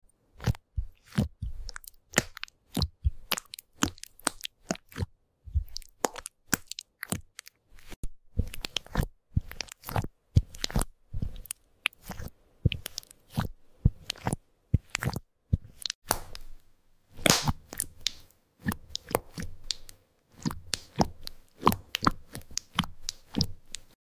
In Part 2 they knead through dreamy crystal slimes: deep blue Lapis Lazuli 💙, iridescent Opal 🌈, and soft pink Rhodochrosite 💗. Relaxing, cute & oddly satisfying vibes you’ll love!